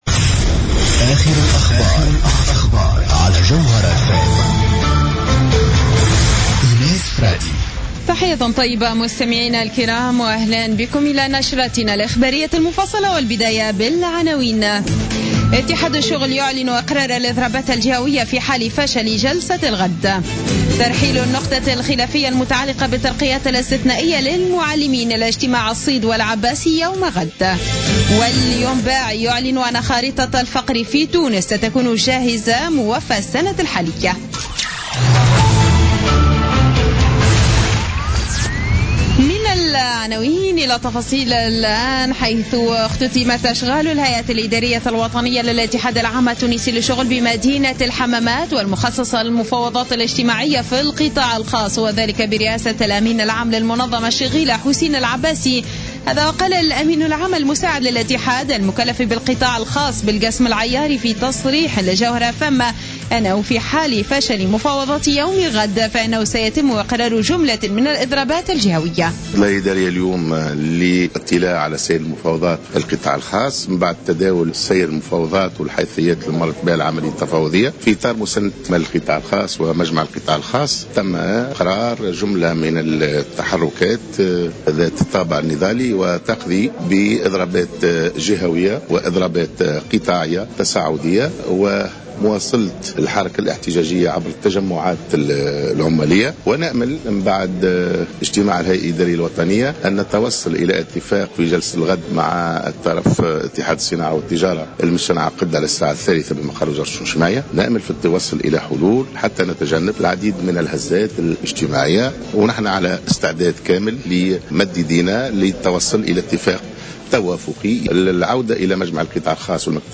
نشرة أخبار السابعة مساء ليوم الأحد 8 نوفمبر 2015